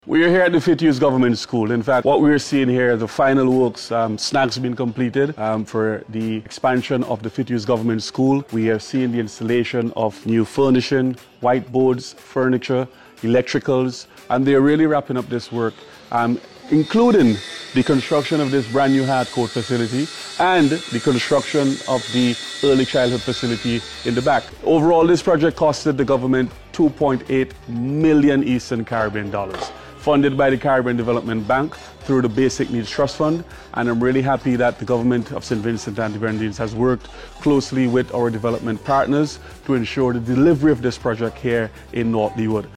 In an interview with the API, James confirmed that finishing touches, including furnishings, a hard court, and the early childhood centre, are in the final stages, and commended the government’s collaboration with other development partners.